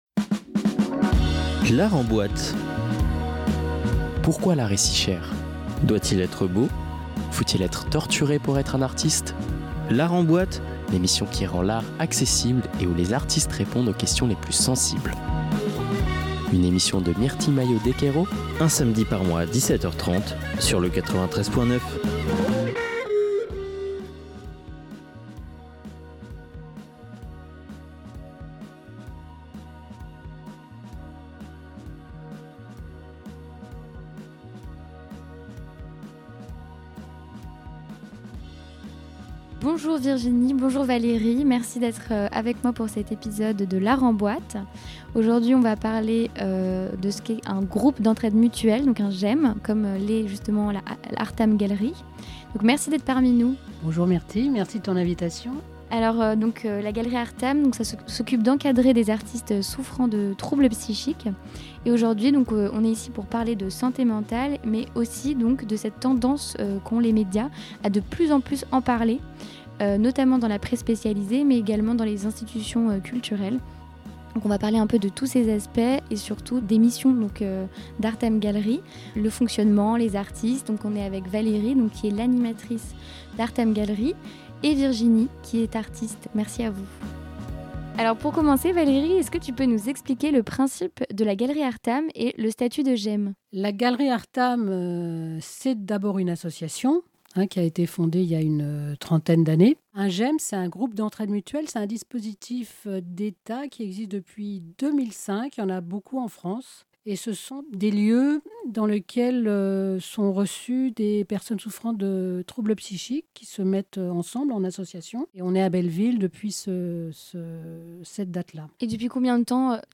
Partager Type Magazine Culture samedi 11 mai 2024 Lire Pause Télécharger La santé mentale dans l'art : un sujet hype ?